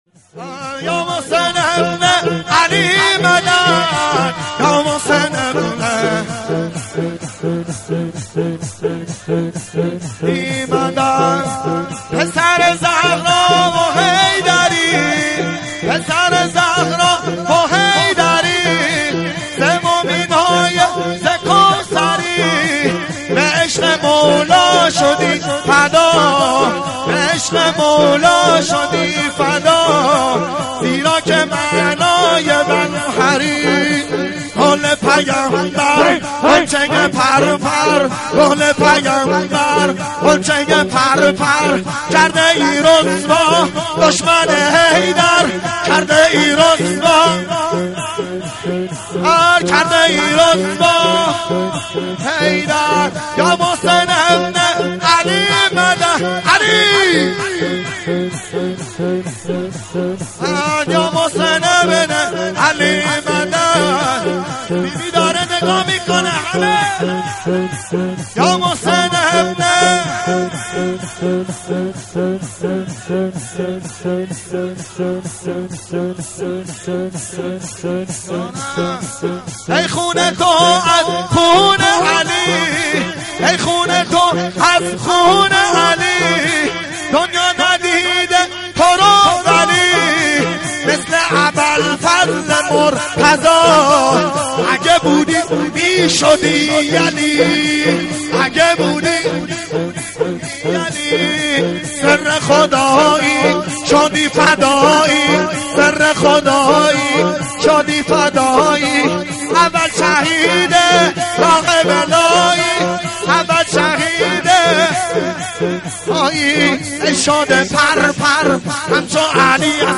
شور
مراسم دهه اول فاطمیه